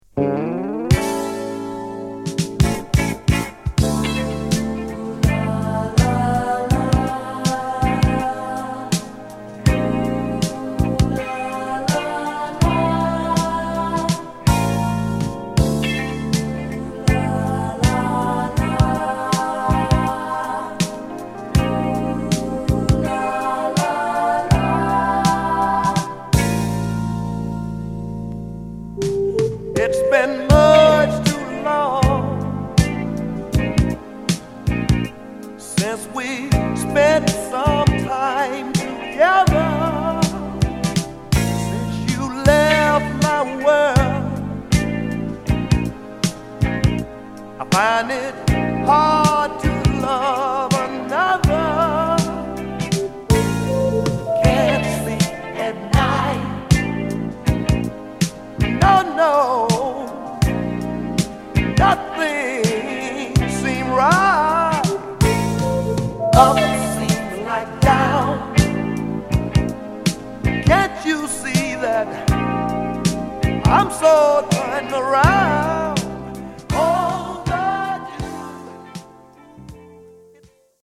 所謂80'sなサウンドを披露
※試聴ファイルは別コピーからの録音です。